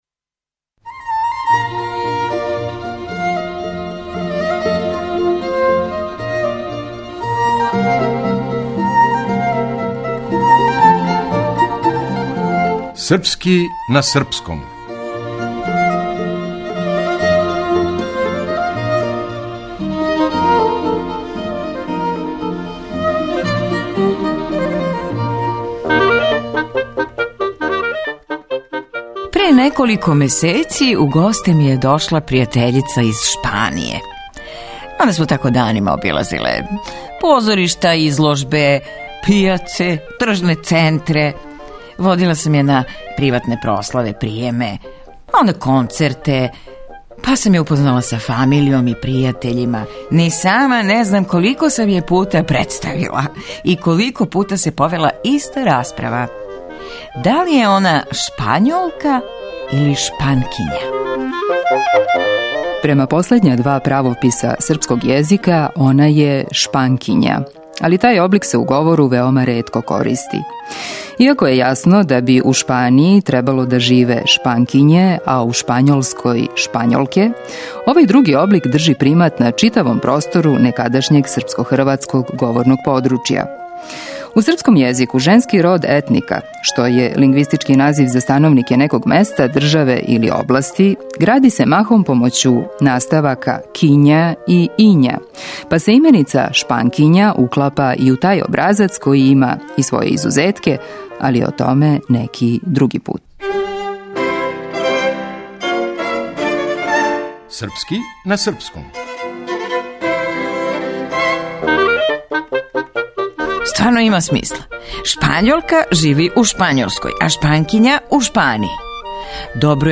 Драмски уметник